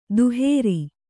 ♪ duhēri